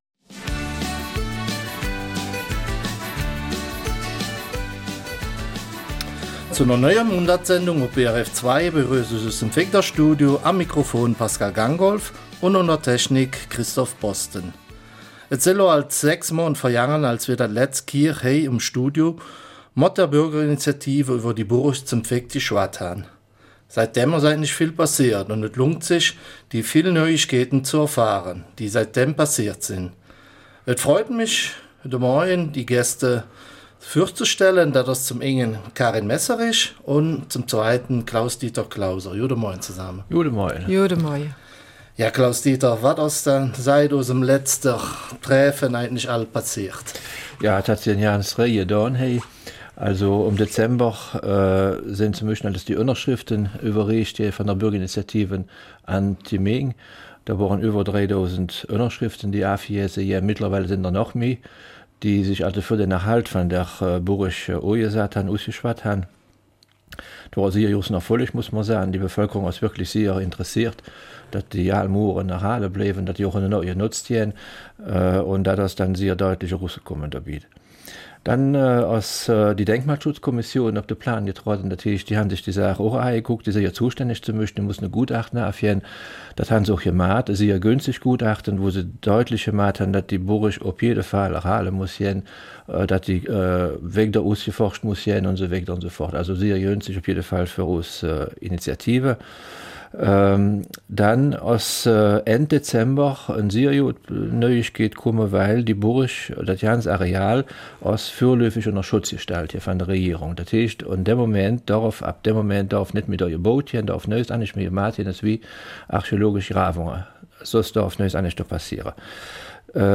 Eifeler Mundart: Burg St. Vith